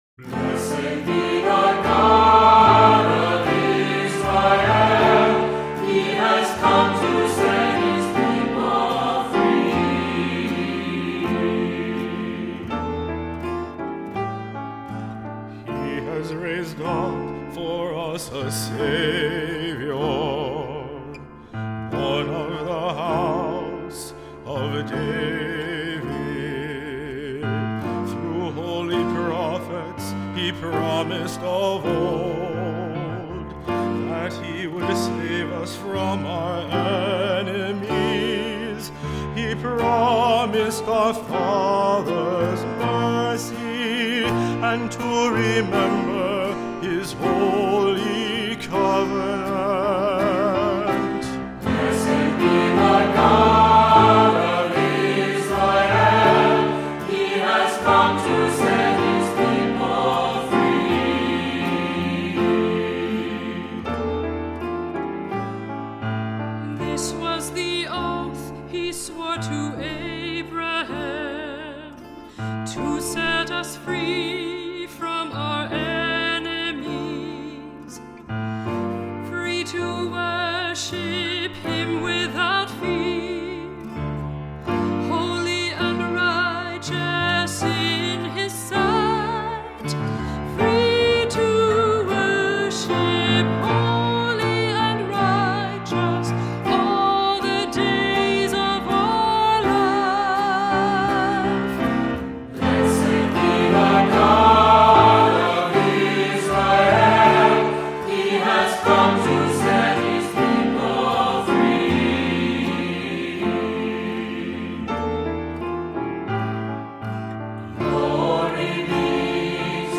Voicing: SATB; Cantor; Assembly